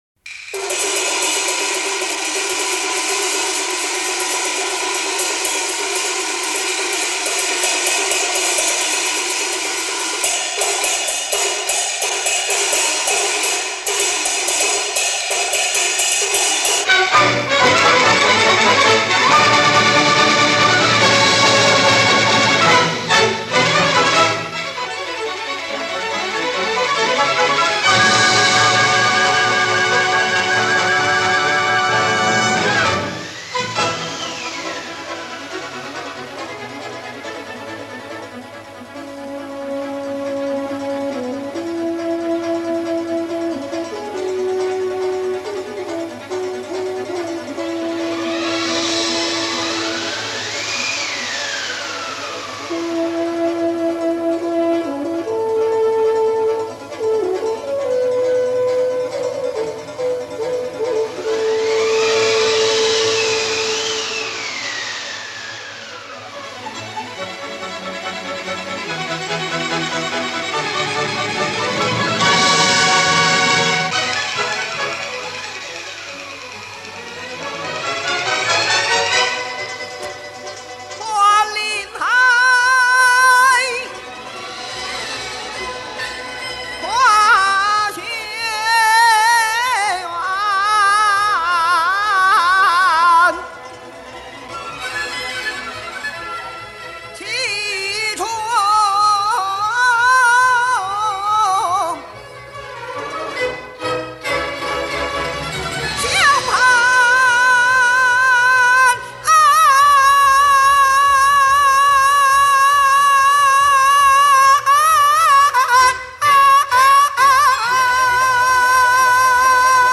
独一无二的魅力唱腔，蕴味浓郁的地方民族风情。
现代京剧